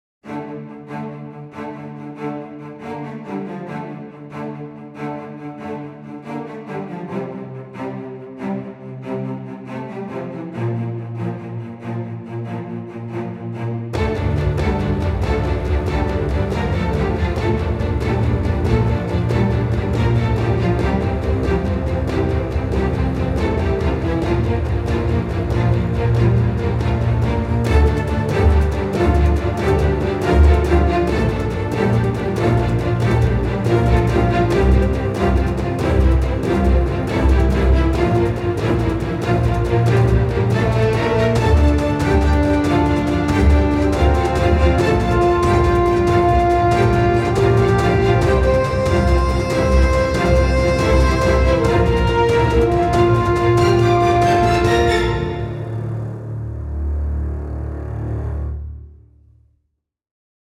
מדהים ביותר -- כמה דברים ממש אהבתי הנגינה מאוד מדויקת ומתוזמנת מעולה וגם מתפתחת כל הזמן ומבינים שאתה מבין במוסיקה ולא רק יודע ללחוץ על כפתורים ממש משמח שיש יוצרים כמוך בפורום וסתם ככה שאלה המנגינה מאוד מוכרת -- מה הכוונה יצרת ?
: מנגינת דרמה שיצרתי.